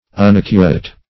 Unaccurate \Un*ac"cu*rate\, a.